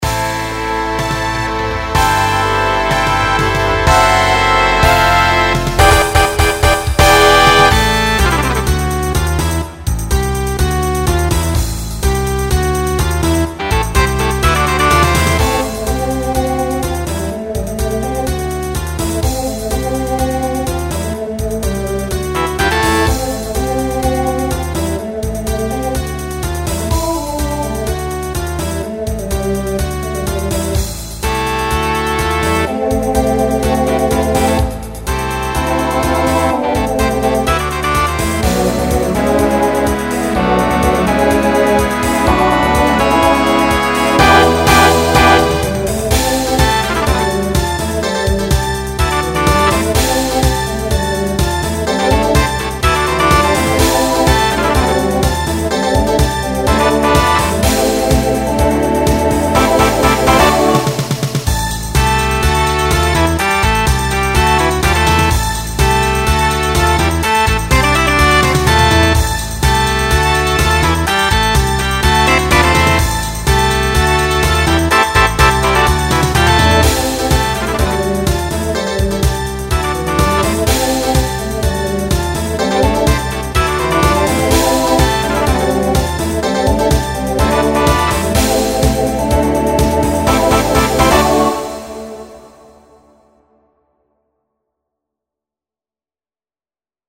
Decade 1980s Genre Pop/Dance
Transition Voicing TTB